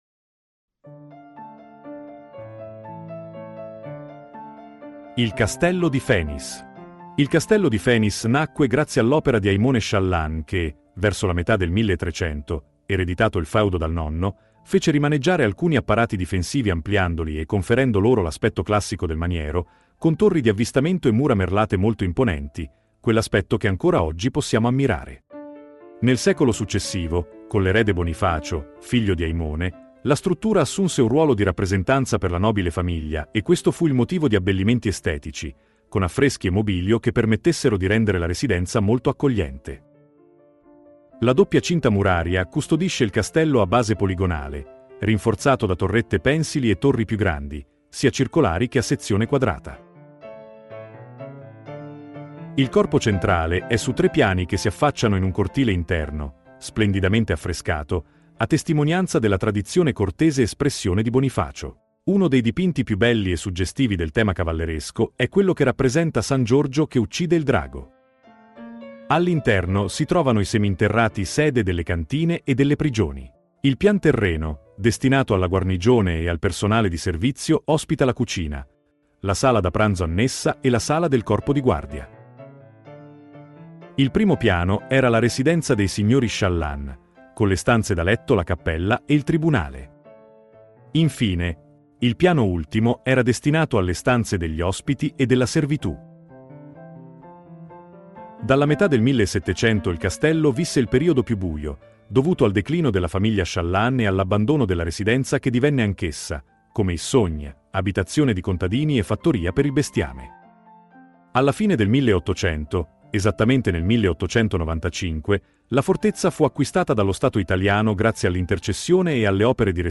Audioguida Castello di Fenis